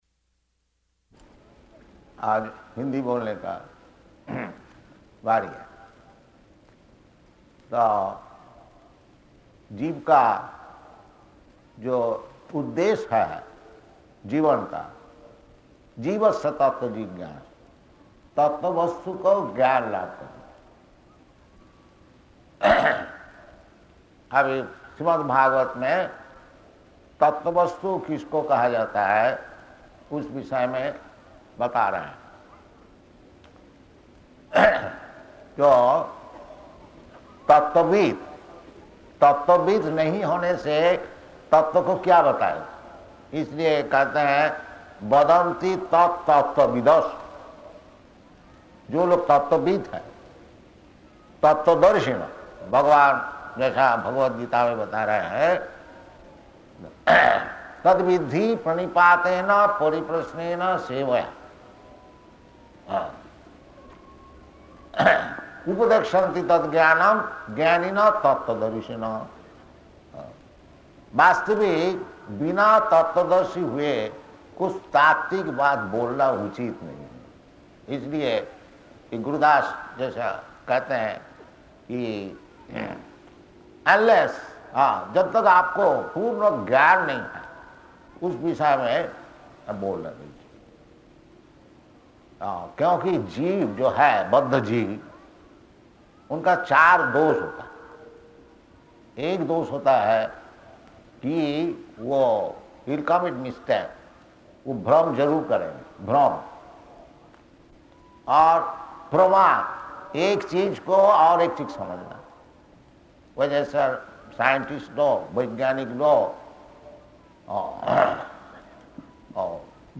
Lecture in Hindi
Type: Lectures and Addresses
Location: Delhi
Lecture at Pandal Śrīmad-Bhāgavatam 1.2.12